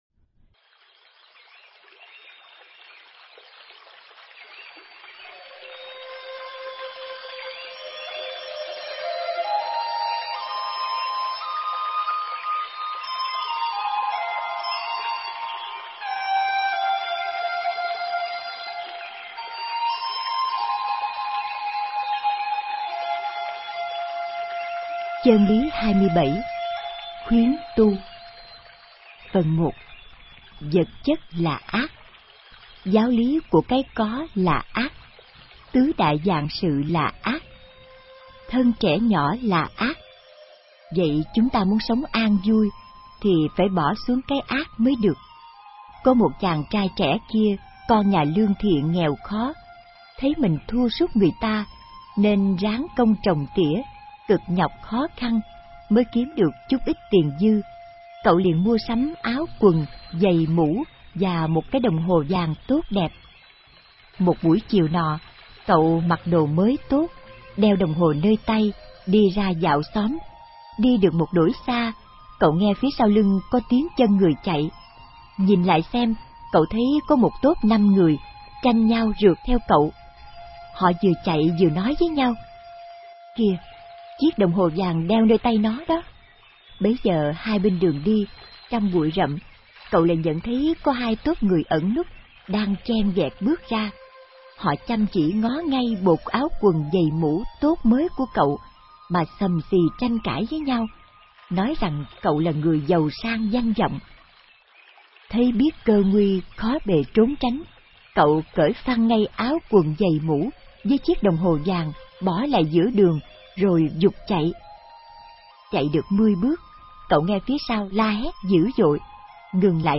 Nghe sách nói chương 27. Khuyến tu